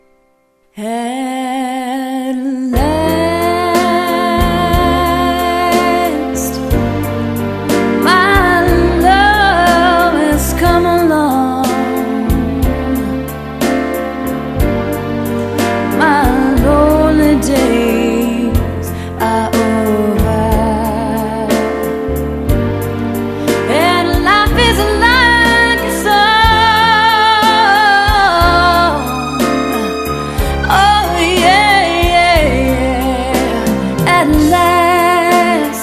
60's Music
Adds A Female Vocalist